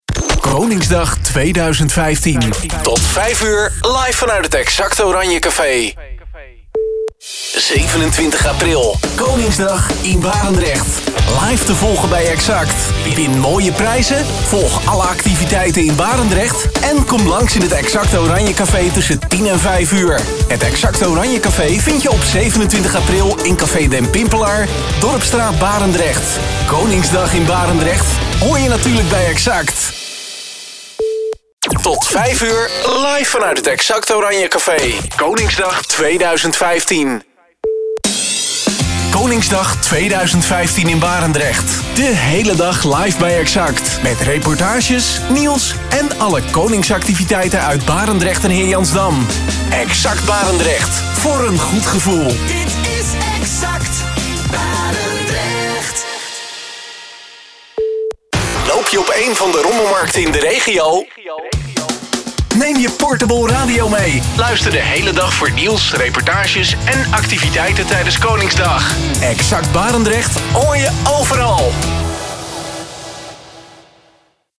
Promo’s en sweepers Exxact Barendrecht